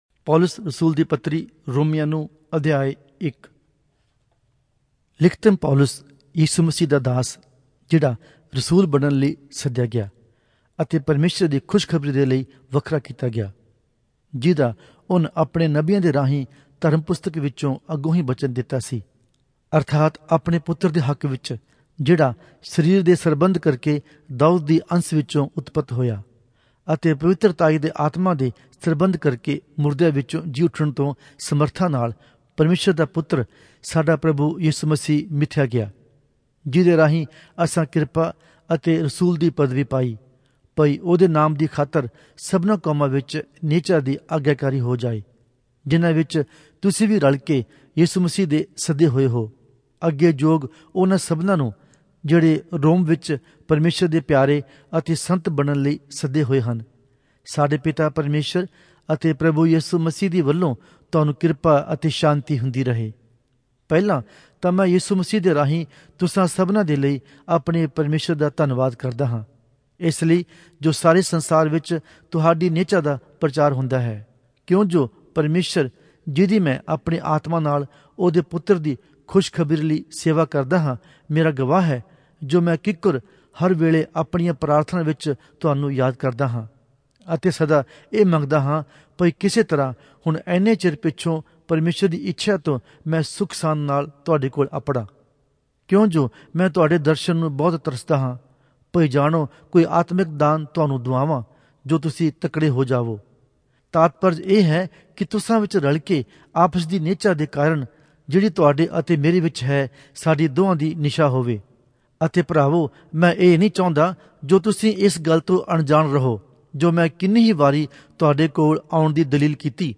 Punjabi Audio Bible - Romans 15 in Gntbrp bible version